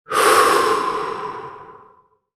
Heavy Sigh Sound Effect
A high-quality recording of a single, deep human sigh characterized by a long, heavy exhale. This sound effect conveys emotions such as relief, exhaustion, frustration, or boredom, making it ideal for use in film, podcasts, or game development.
Heavy-sigh-sound-effect.mp3